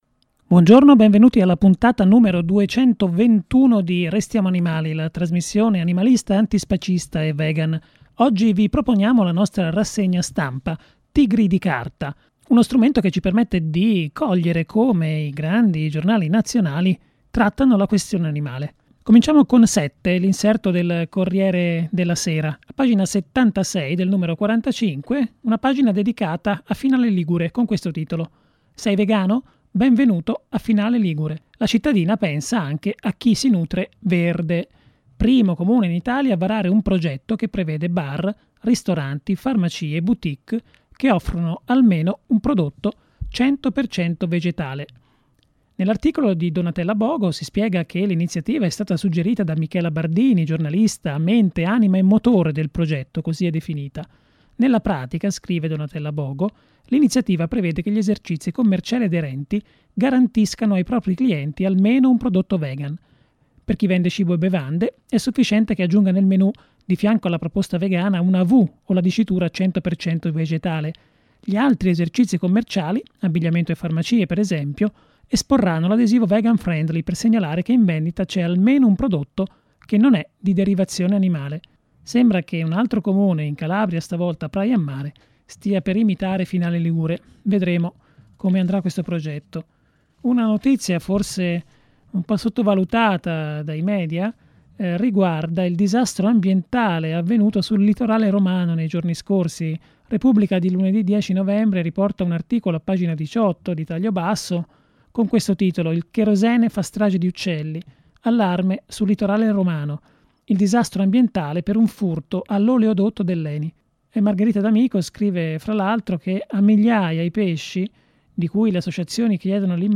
Nella puntata numero 221 del 13 novembre 2014, la rassegna stampa altranimalista “Tigri di carta”.